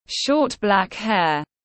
Short black hair /ʃɔːt blæk heər/